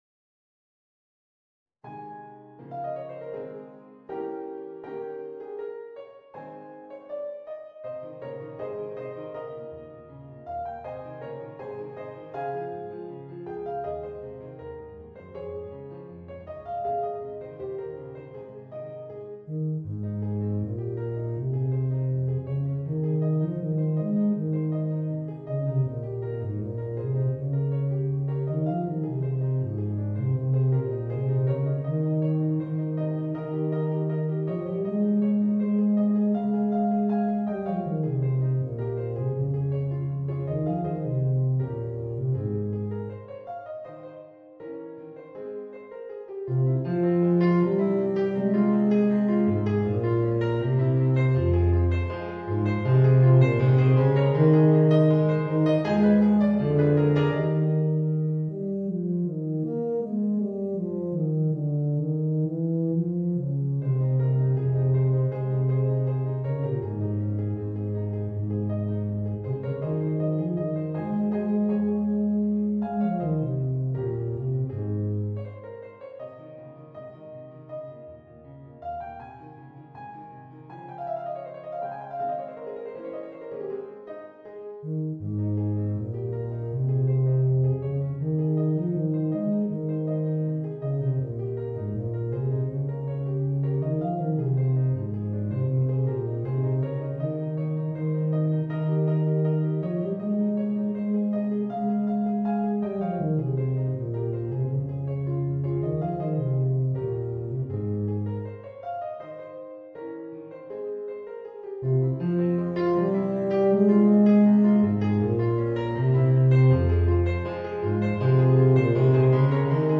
Voicing: Eb Bass and Organ